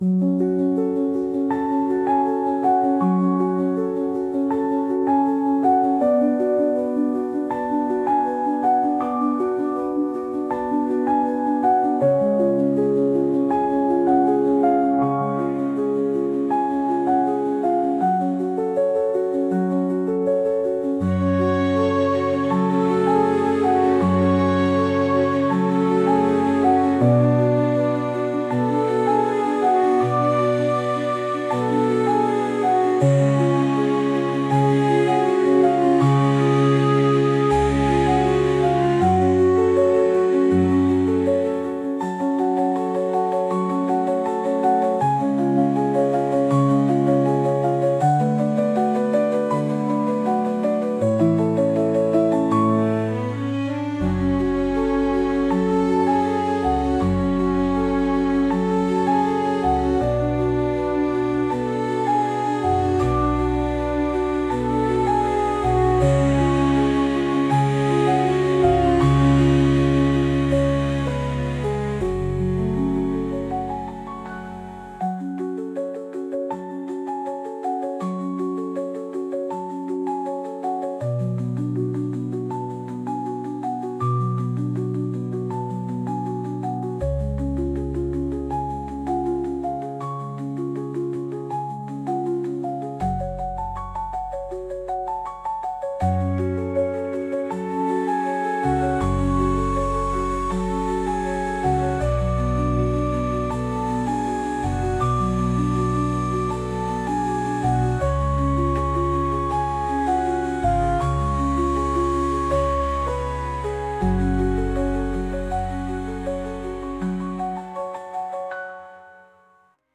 2015 & Instrumentální hudba a Obrázek: AI